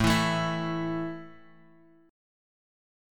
A5 chord {x 0 2 2 x 0} chord
A-5th-A-x,0,2,2,x,0.m4a